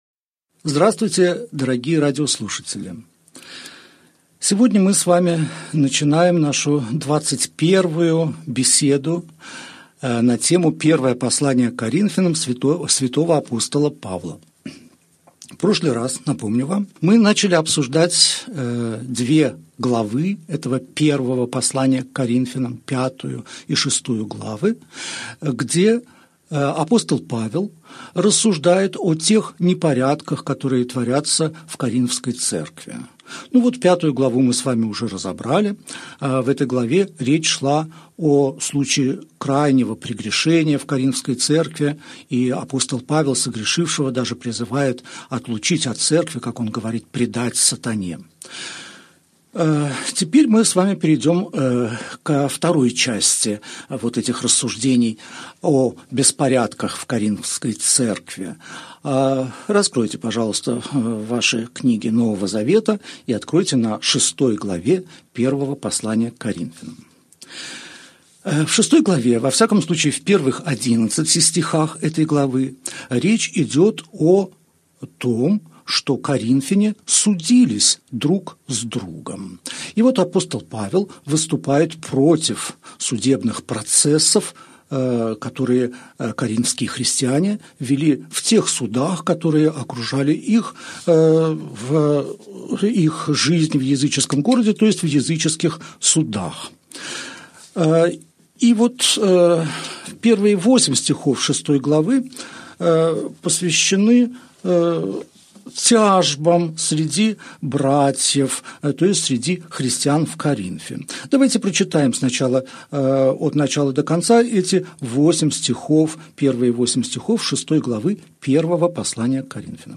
Аудиокнига Беседа 21. Первое послание к Коринфянам. Глава 6, стихи 1 – 11 | Библиотека аудиокниг